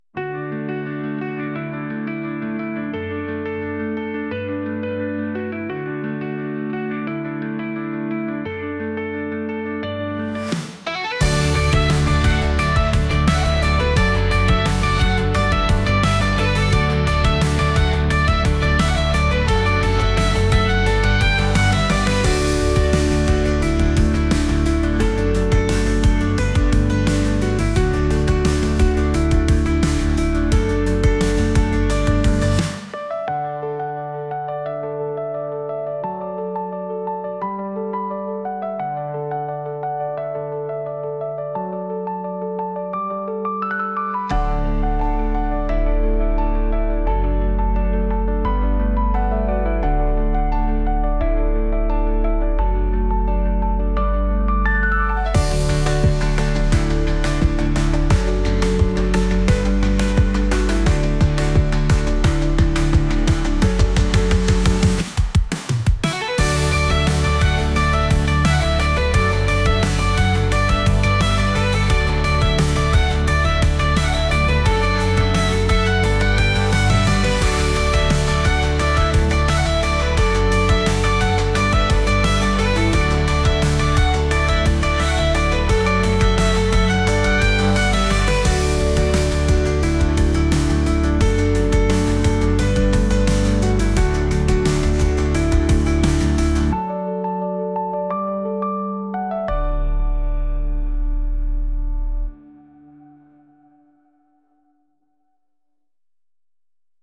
エレキとバイオリンとピアノの元気が出るような曲です。